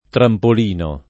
[ trampol & no ]